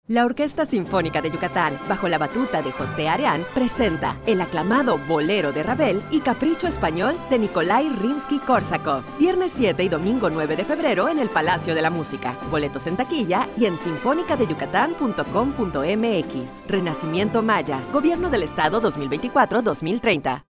Spot de radio
Spot-de-radio-P3.mp3